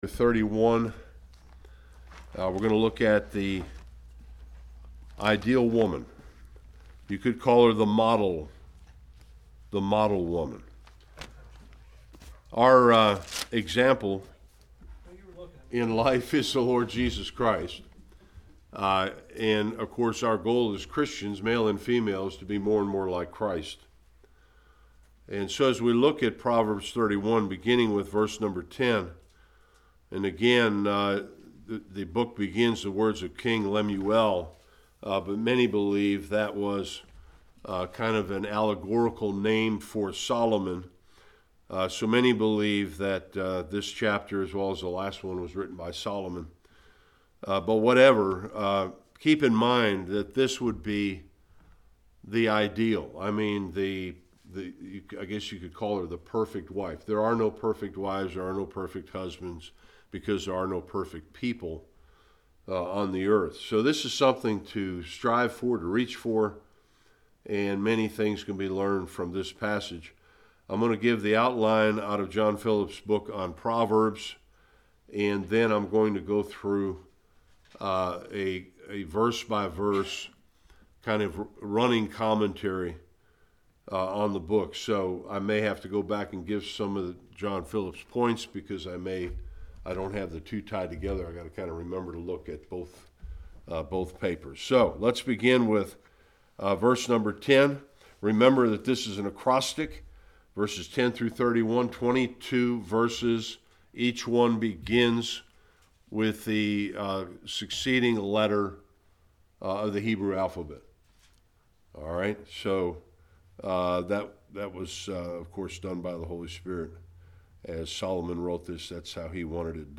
10-31 Service Type: Bible Study The Biblical description of the ideal wife and mother.